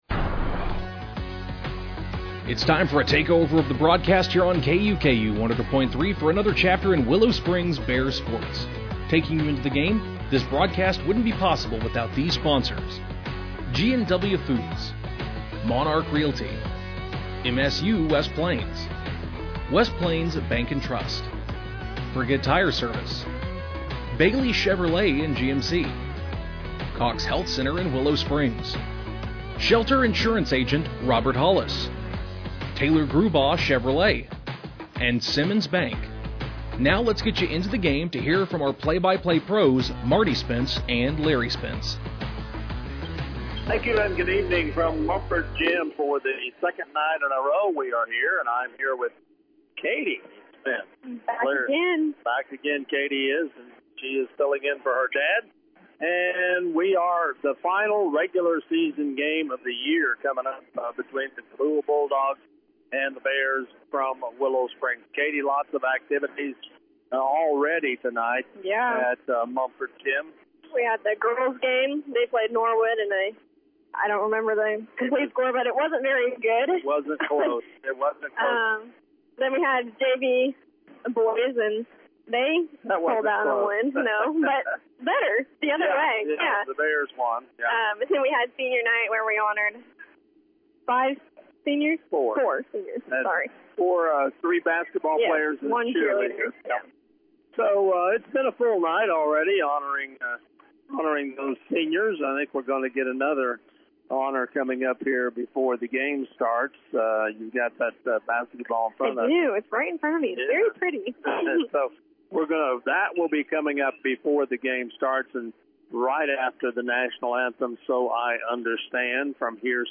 Game Audio Below: Cabool won the tip to start the game.
Willow-Springs-Bears-vs.-Cabool-Bulldogs-2-20-26.mp3